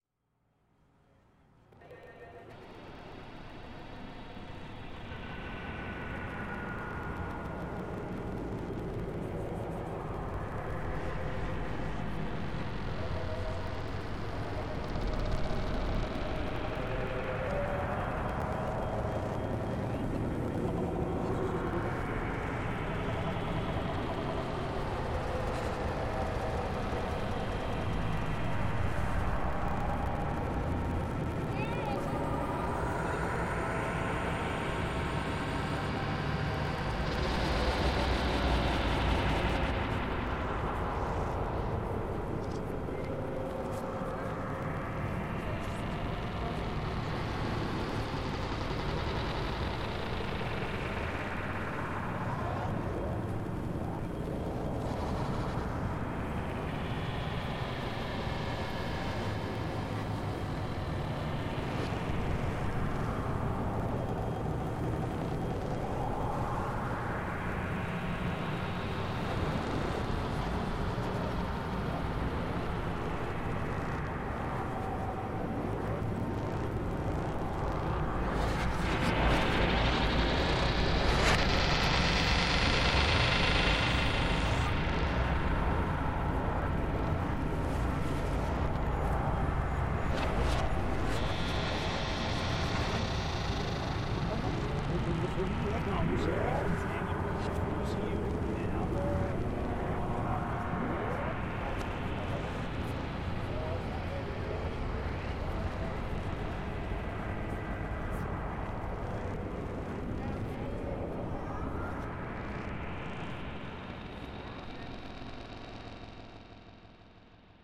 A remix of work going on in Burano, reflecting the break in calm caused by the machine boat in an otherwise-tranquil spot.